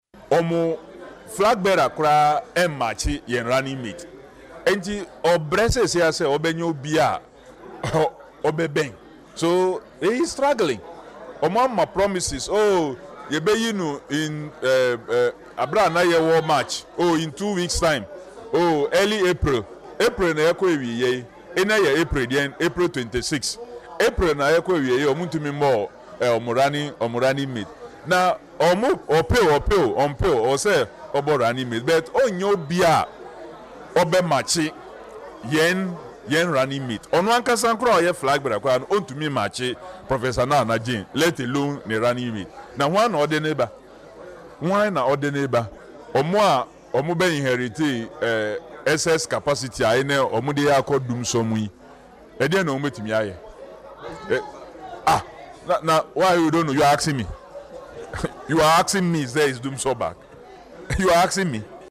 Speaking in an interview with Adom News, Mr. Adams said Dr. Bawumia is struggling to find a suitable candidate to match the NDC running mate boot-for-boot.